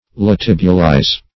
Search Result for " latibulize" : The Collaborative International Dictionary of English v.0.48: Latibulize \La*tib"u*lize\, v. i. [imp.